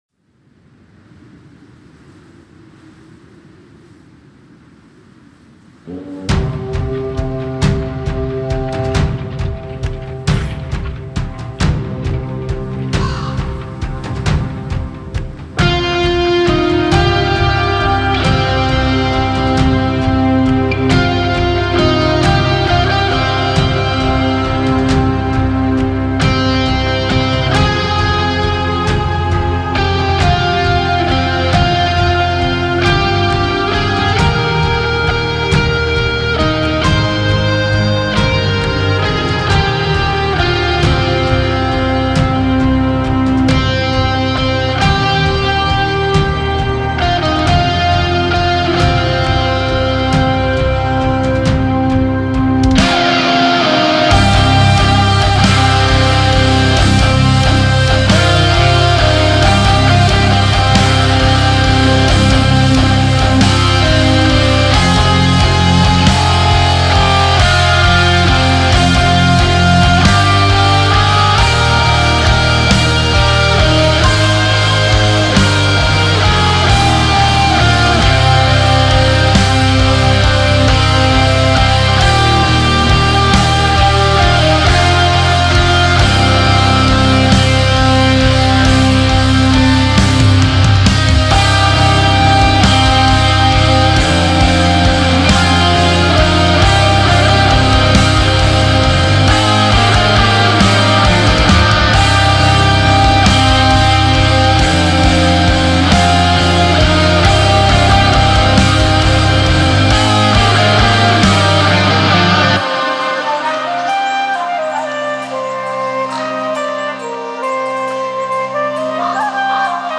Черный_ворон___Nordic_metal